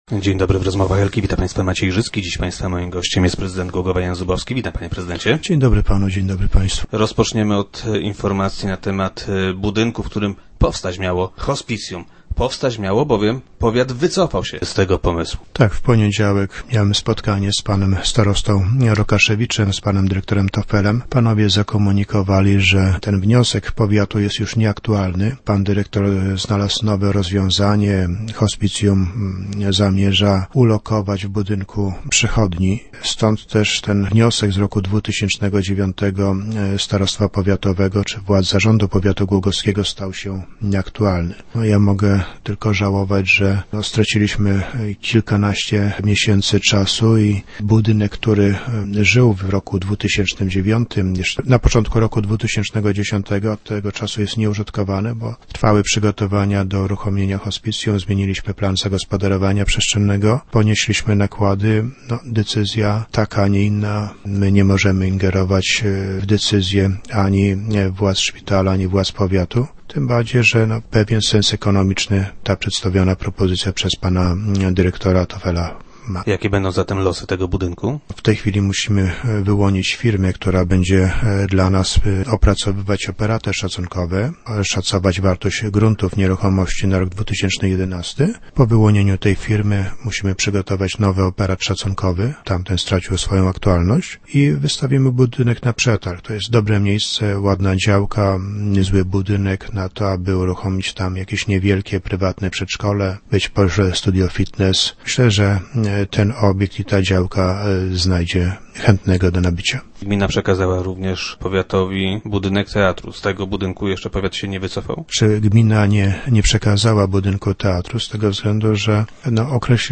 - Za parkowanie będzie można też płacić SMS-ami - informuje prezydent Głogowa Jan Zubowski, który był gościem Rozmów Elki.